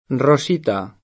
vai all'elenco alfabetico delle voci ingrandisci il carattere 100% rimpicciolisci il carattere stampa invia tramite posta elettronica codividi su Facebook Rosita [ ro @& ta ; sp. + ro S& ta ] pers. f. (= Rosa) — cfr.